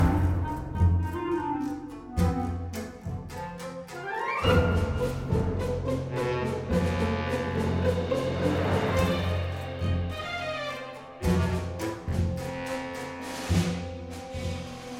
Musique multipistes.
Pistes : 10 (dont des cœurs)